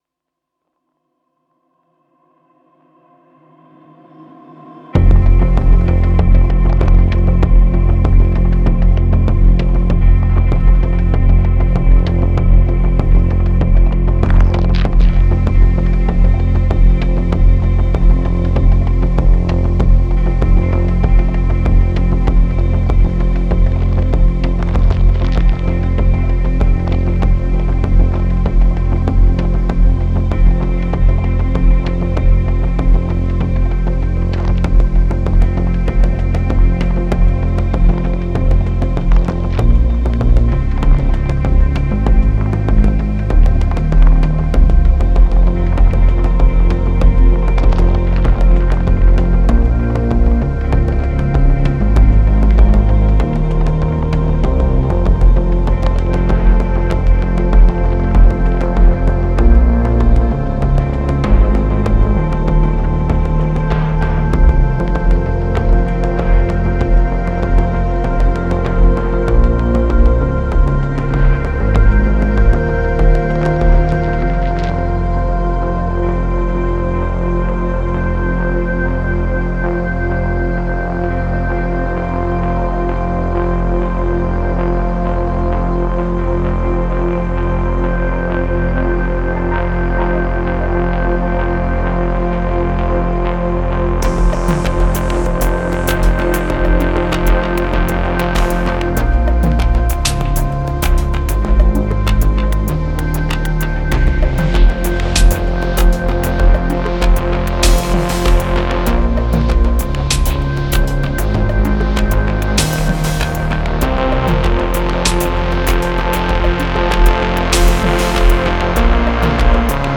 Cinematic Electronica